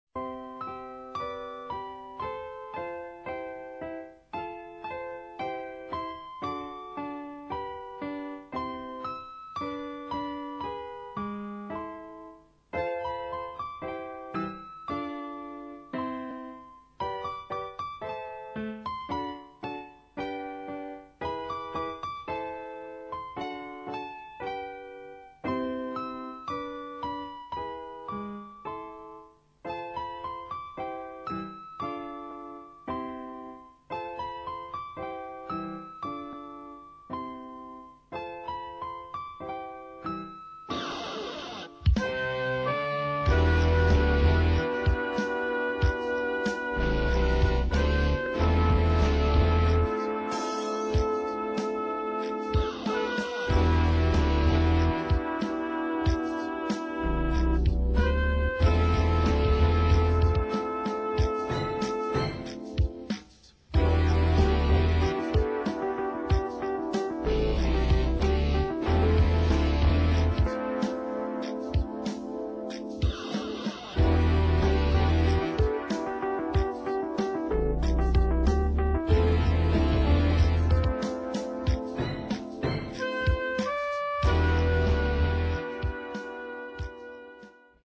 "live on stage!"